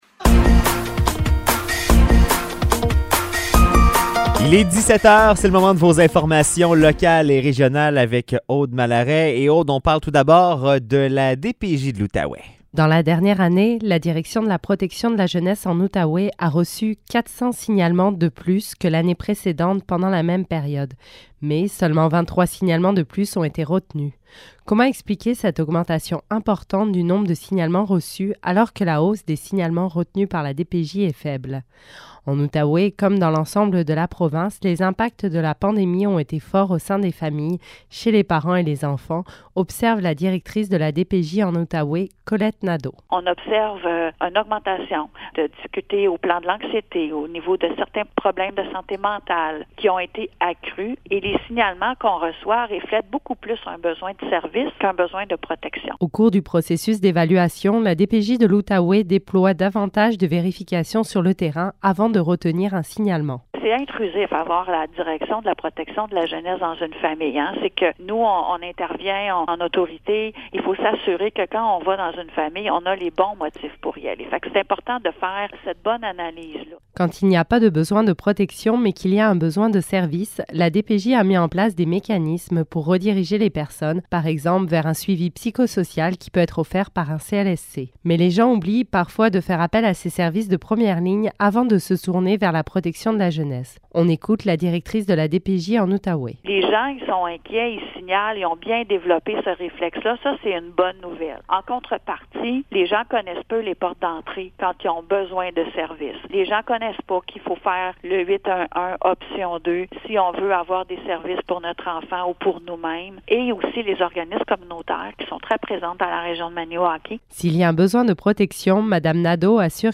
Nouvelles locales - 22 novembre 2022 - 17 h